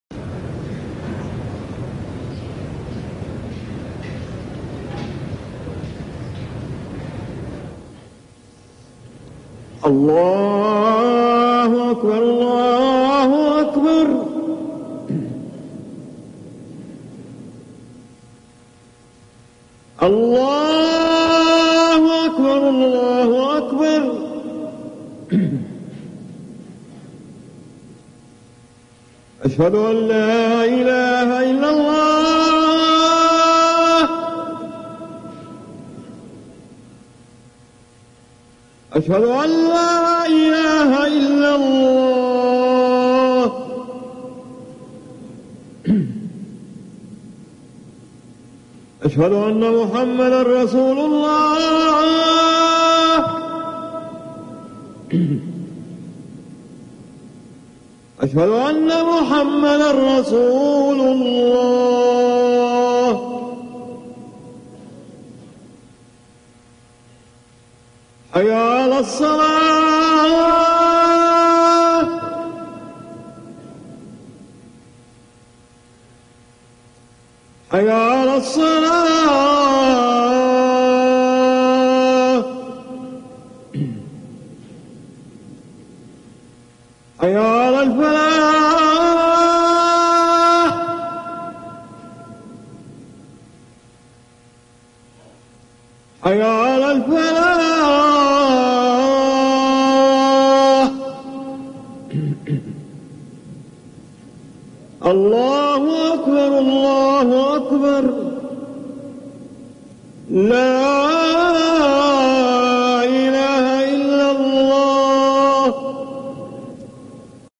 أذان
المكان: المسجد النبوي الشيخ